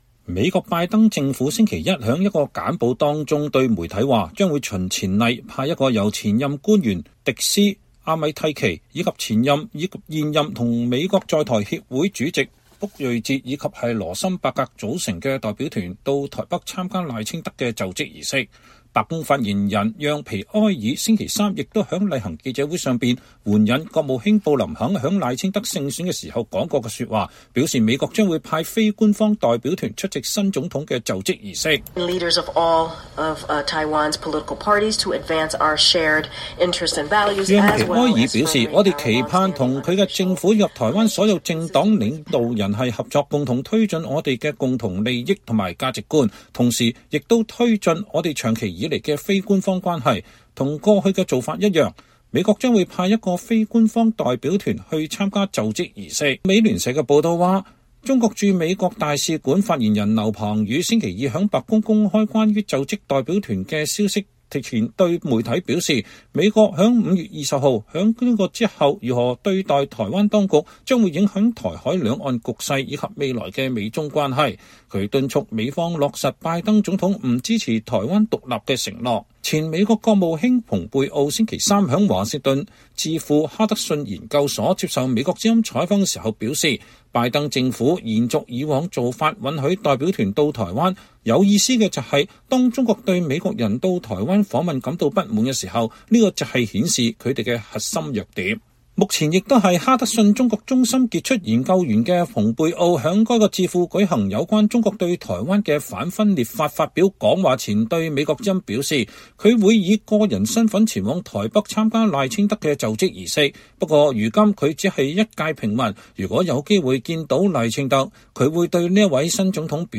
前美國國務卿蓬佩奧週三在華盛頓智庫哈德遜研究所(Hudson Institute)接受美國之音(VOA)採訪時說，拜登政府延續以往做法允許代表團去台灣，有意思的是，中國會對美國人到台灣訪問感到不滿，那只是顯示了他們的核心弱點。